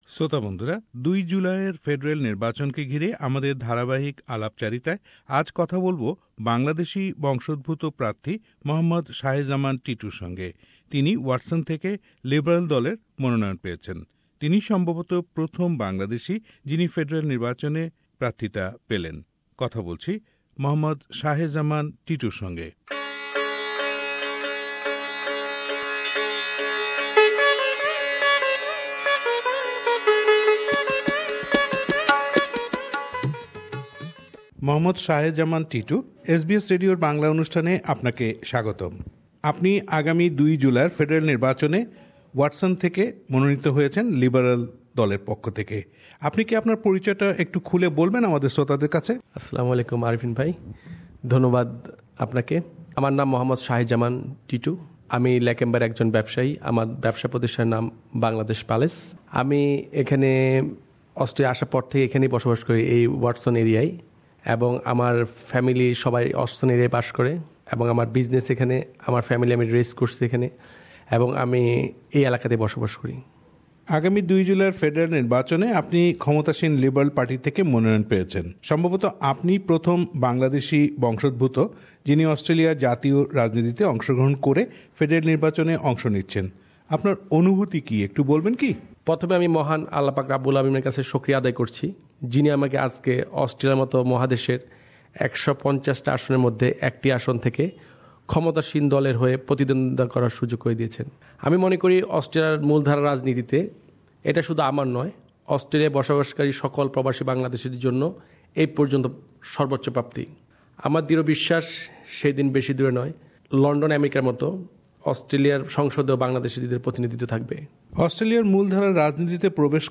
Election 2016 :Interview